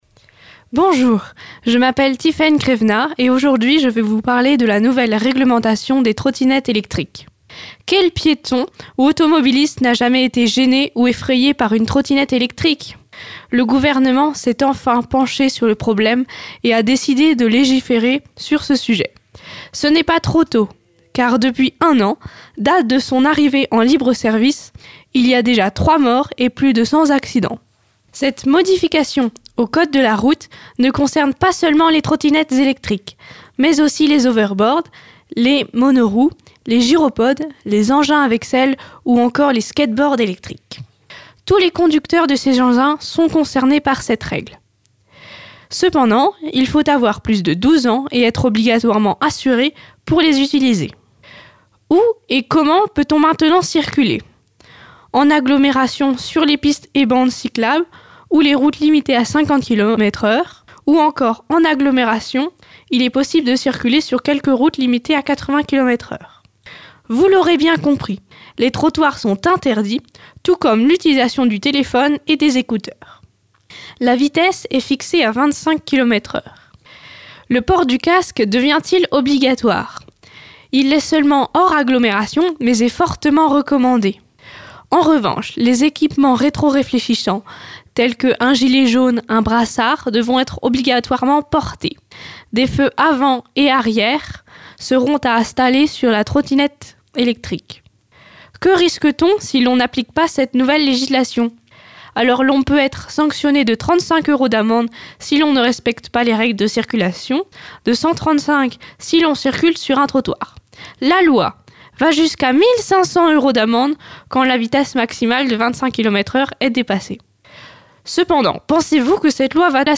Pour télécharger cet enregistrement réalisé dans les studios de Fidélité Mayenne ,